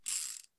Revolver Reload.wav